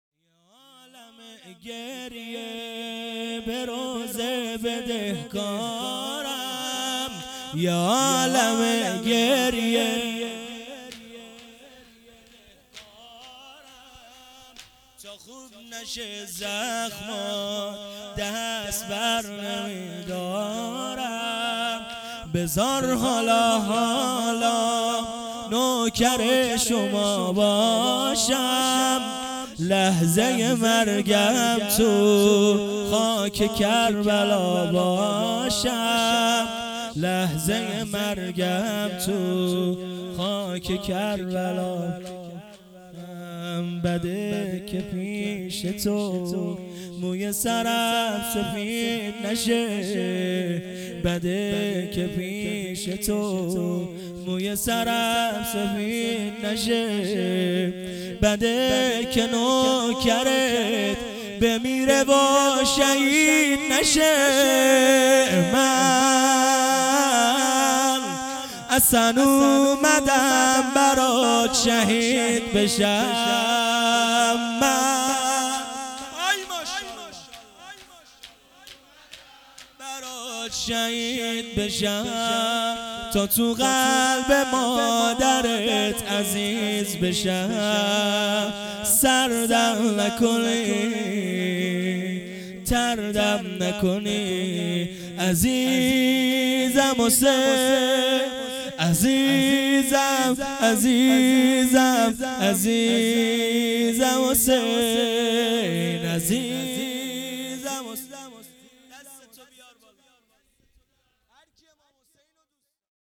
مراسم سالگرد شهادت سپهبد حاج قاسم سلیمانی ۱۱ دی ۱۳۹۹